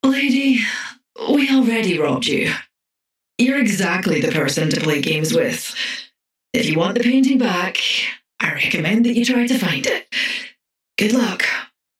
Paradox voice line - Lady, we already robbed you.